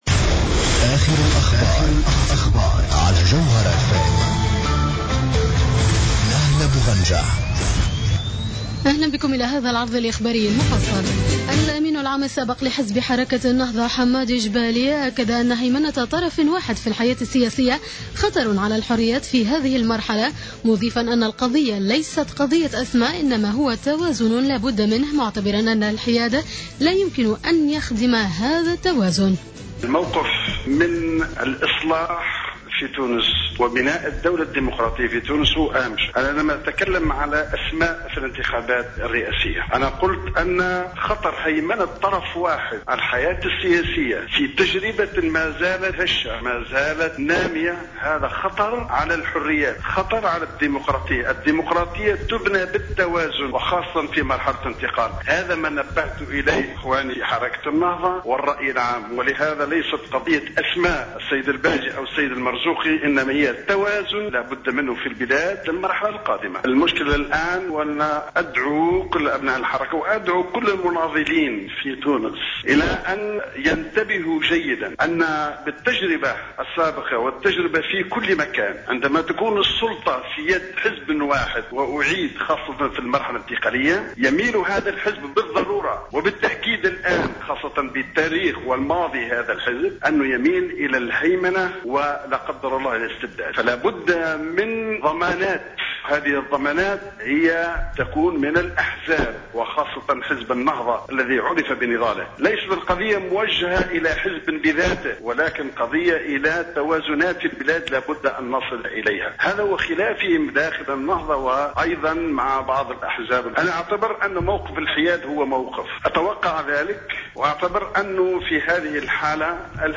نشرة أخبار منتصف الليل ليوم 13-12-14